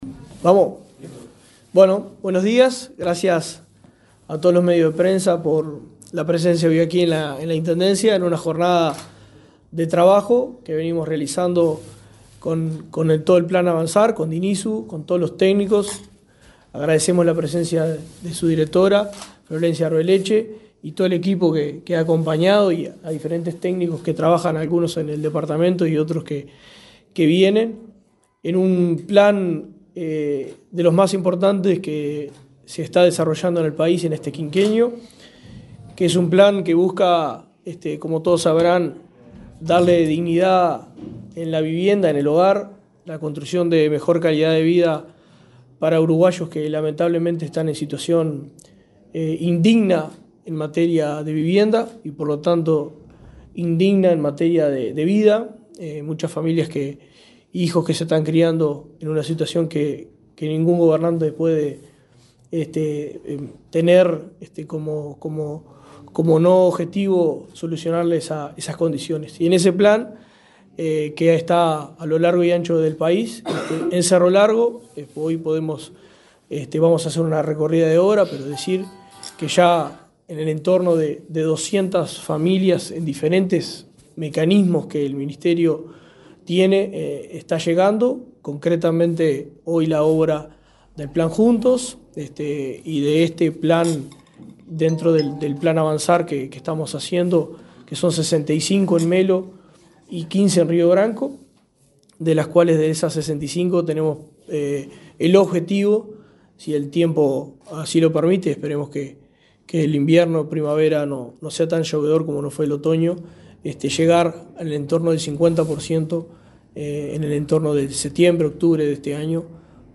Conferencia de prensa en Cerro Largo
El intendente de Cerro Largo, José Yurramendi, y la directora nacional de Integración Social y Urbana del Ministerio de Vivienda, Florencia Arbeleche,